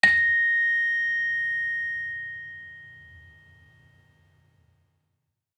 Gamelan Sound Bank
Saron-5-A#4-f.wav